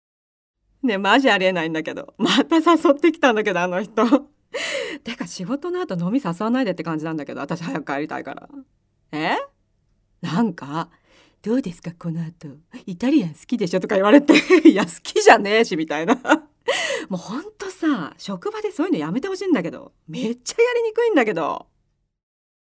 ボイスサンプル
OL